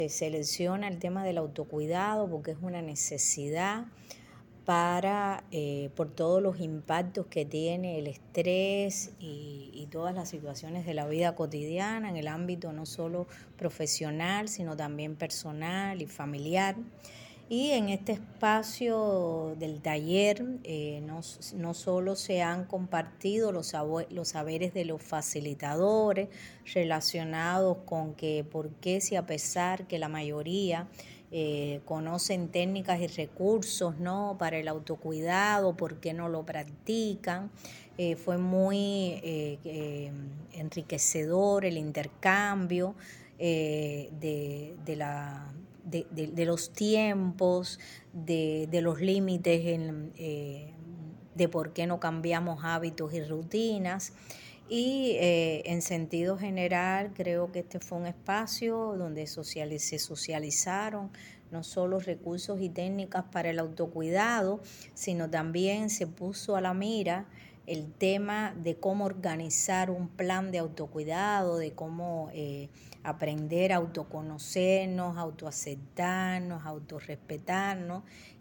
Entrevista-sicologa-.mp3